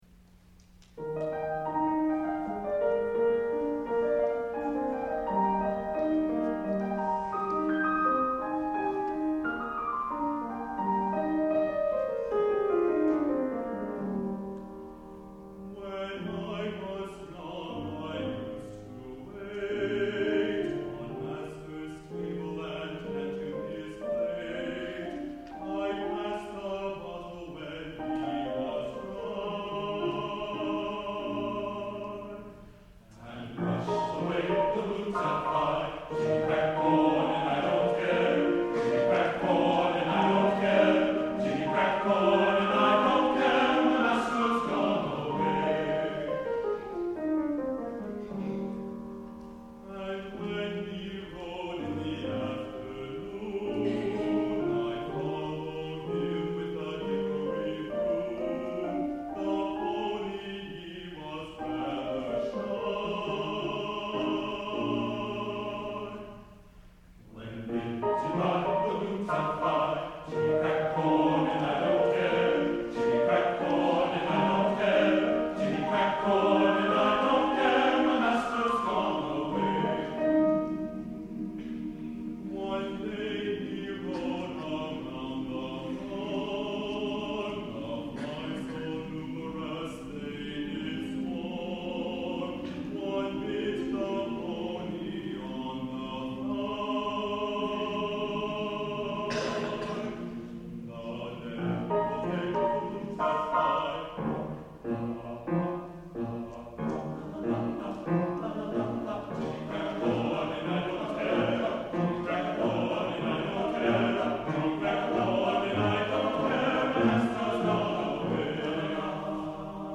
TTBB (4 voices men).